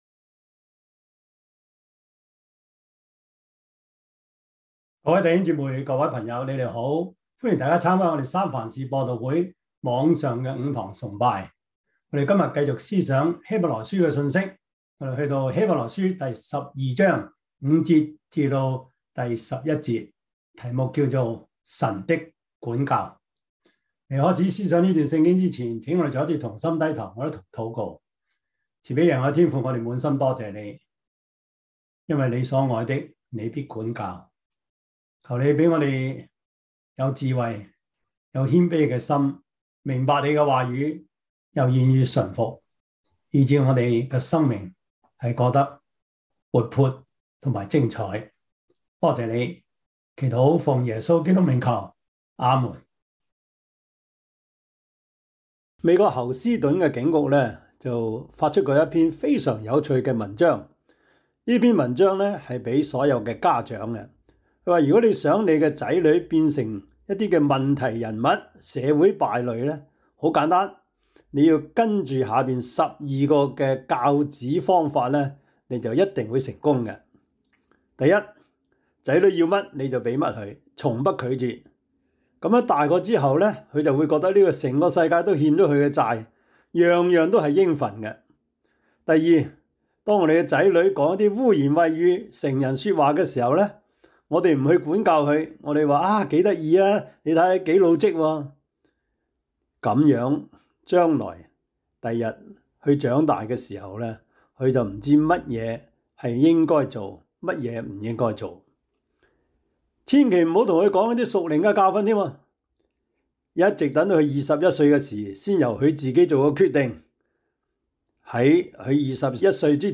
Service Type: 主日崇拜
Topics: 主日證道 « 尋回突破的盼望 第三十三課: 北京基督徒學生會 »